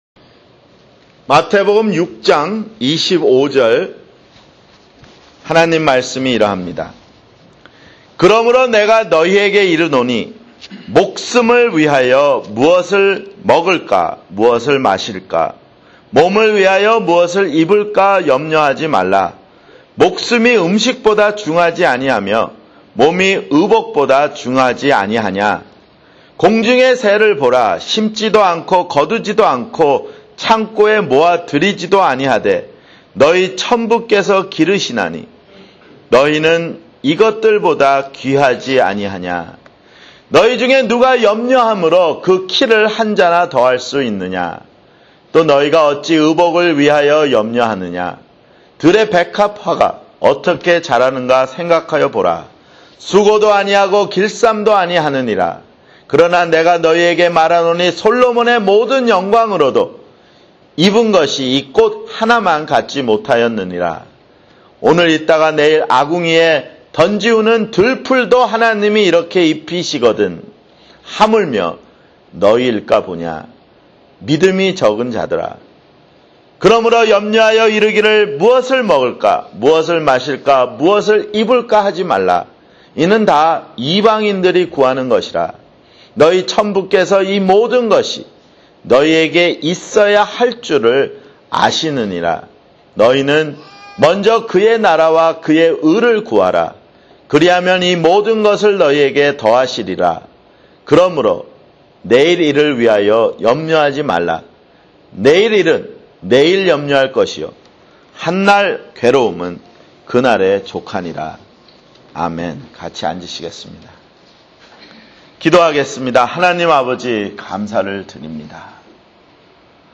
[주일설교] 마태복음 (42)